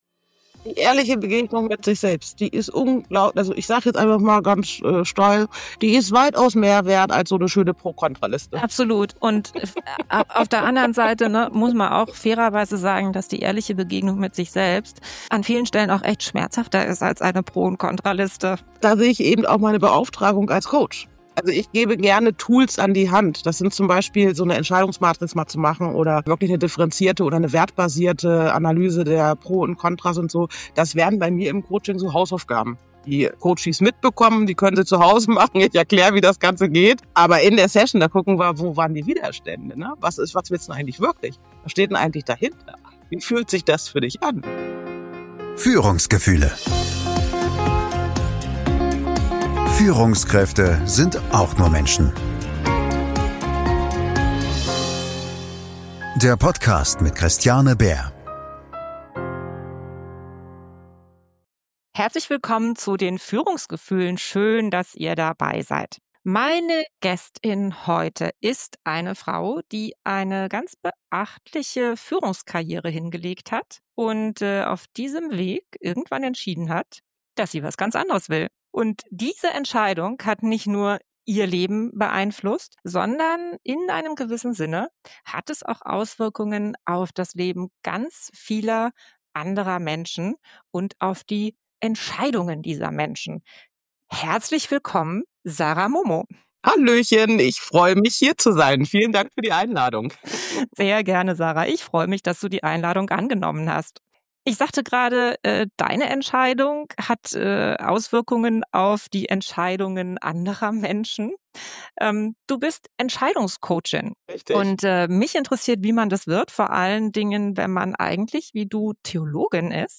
Mut zur Leere – Entscheidungen, die das Leben verändern - Gespräch